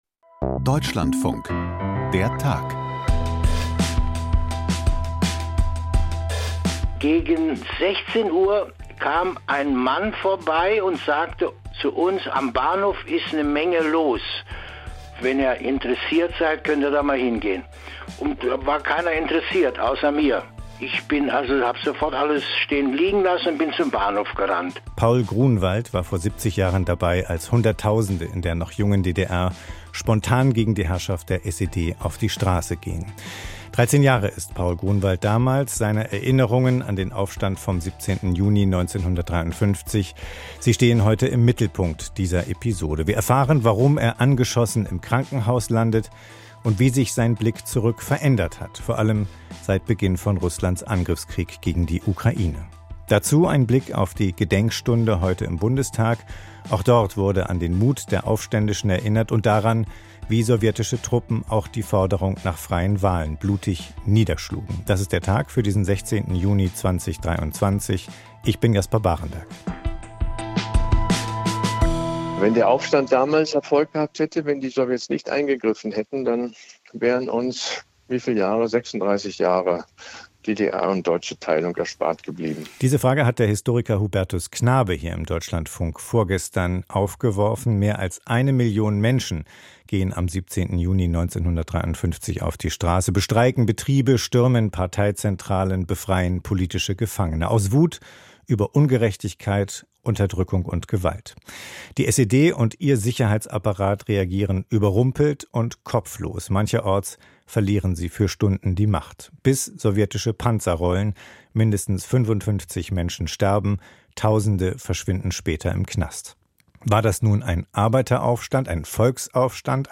Ein Zeitzeuge erinnert sich an den 17. Juni 1953 - und an die Kugel, die ihn traf.